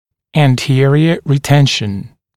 [æn’tɪərɪə rɪ’tenʃn][эн’тиэриэ ри’тэншн]ретенция во фронтальном отделе